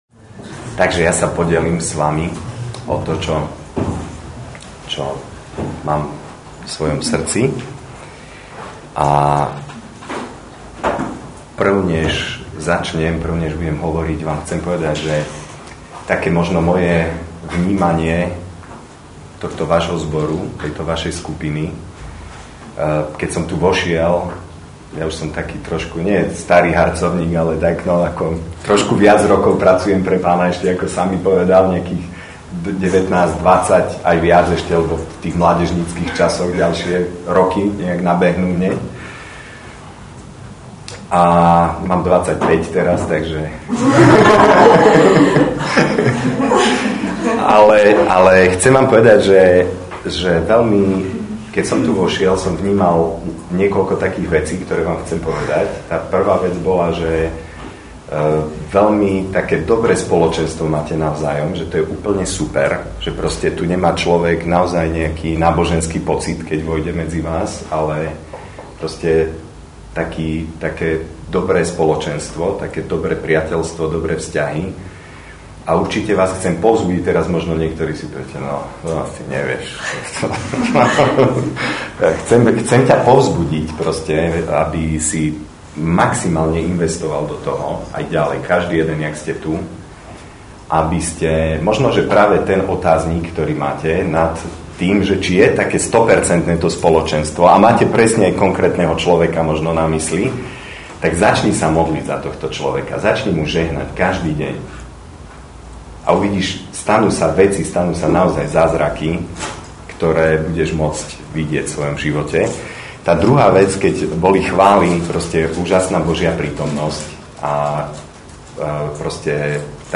Nahrávka kázne Kresťanského centra Nový začiatok z 16. augusta 2009